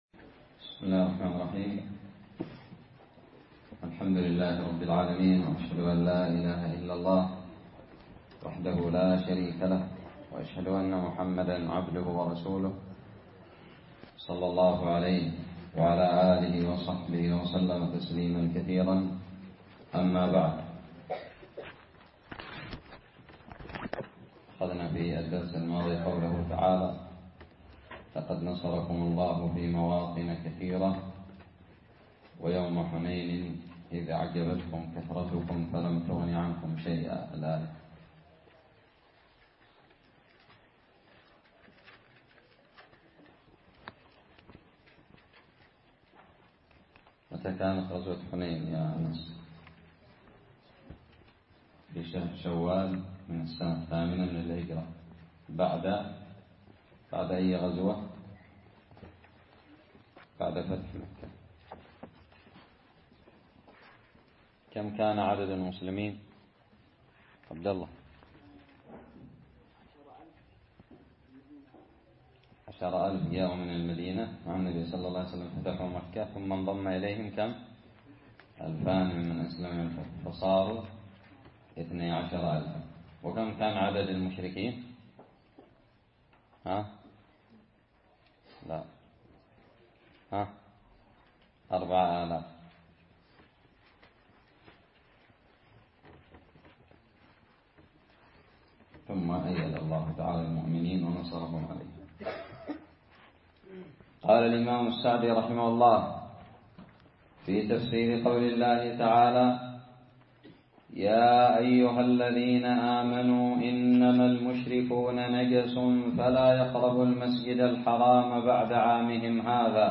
الدرس الثاني عشر من تفسير سورة التوبة
ألقيت بدار الحديث السلفية للعلوم الشرعية بالضالع